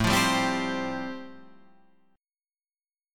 Am7#5 chord